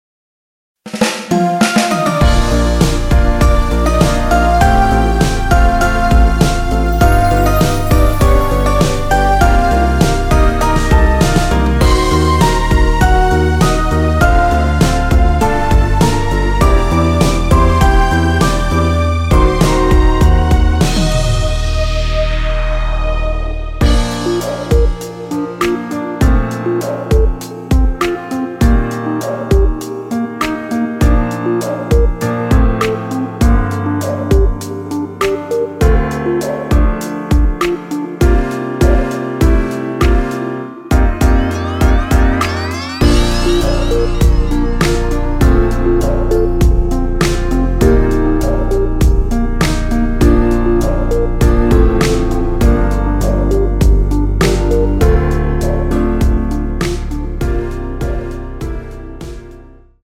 원키에서(-1)내린 MR입니다.
Bb
앞부분30초, 뒷부분30초씩 편집해서 올려 드리고 있습니다.